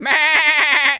pgs/Assets/Audio/Animal_Impersonations/goat_baa_stressed_hurt_02.wav at master
goat_baa_stressed_hurt_02.wav